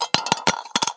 硬币掉入铁罐 " 硬币掉落10
描述：单枚硬币掉进一个罐子里